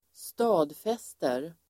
Ladda ner uttalet
Uttal: [²st'a:dfes:ter]